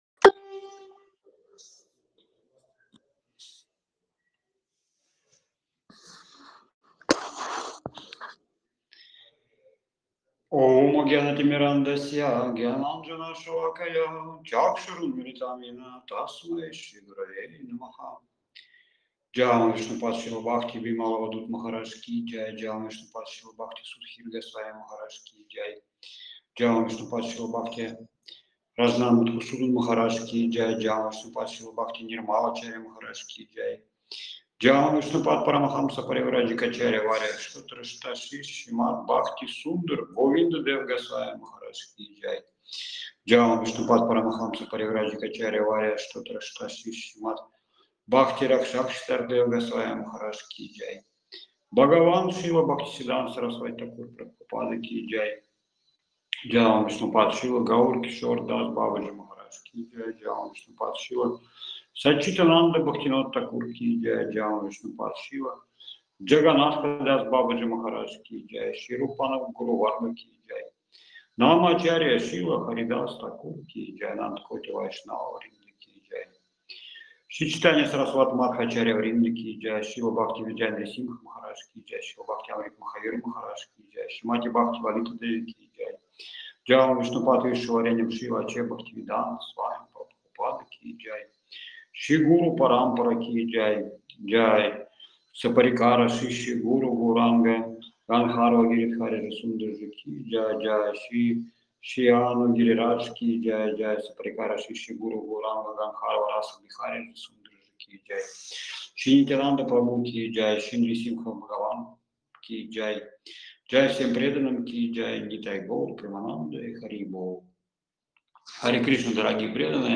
Опубликовано: 19 ноября 2024 Вещание из Говардхан-дхамы.
Лекции полностью